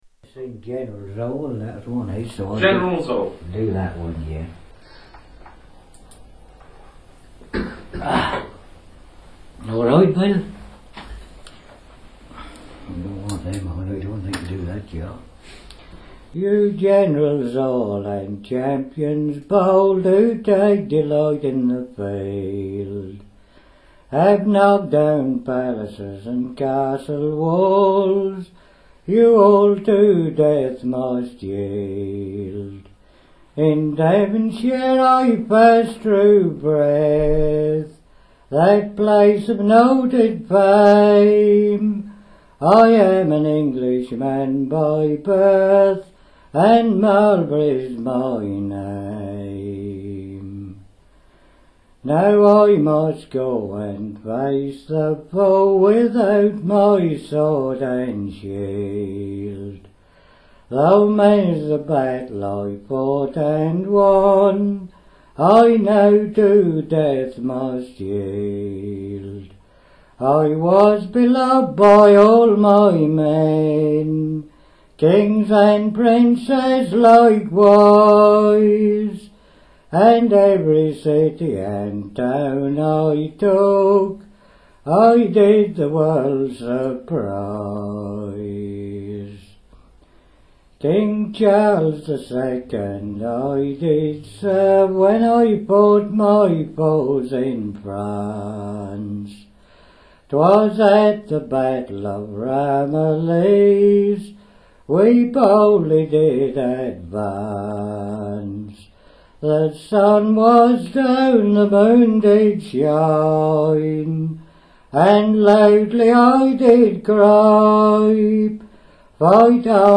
Wax cylinder records